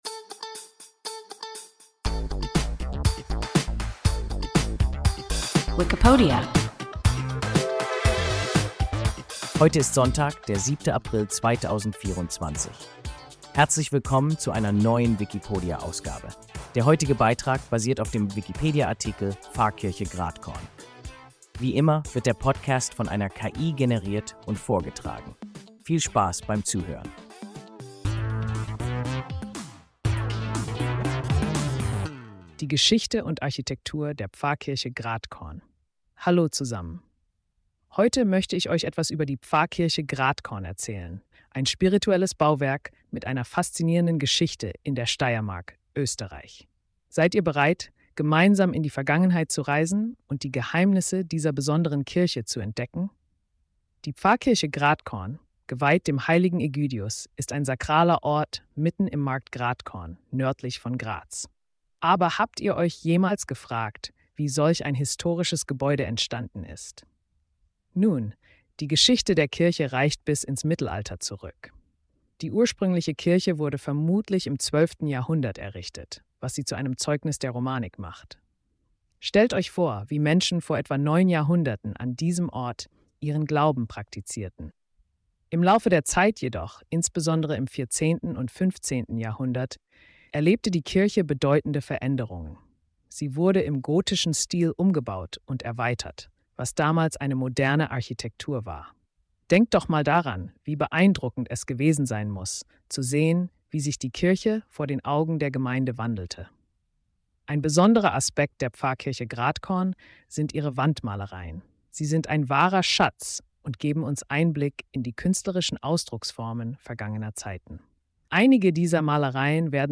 Pfarrkirche Gratkorn – WIKIPODIA – ein KI Podcast